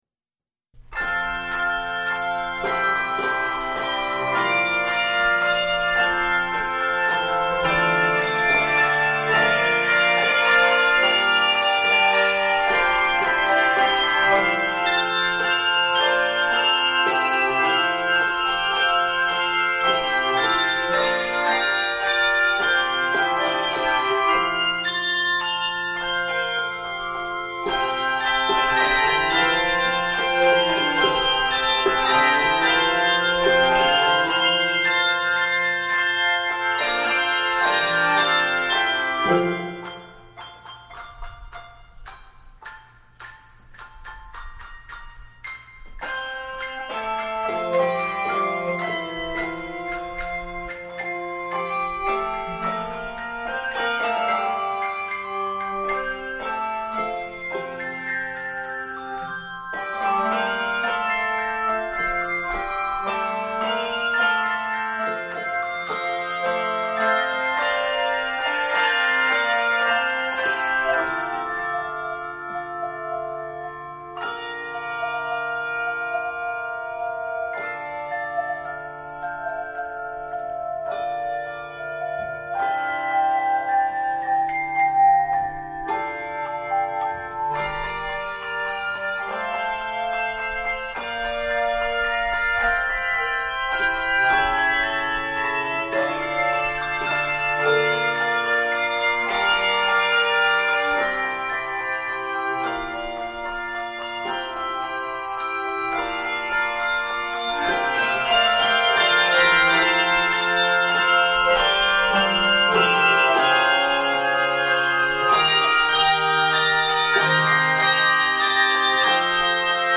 handbells
Key of F Major. 87 measures.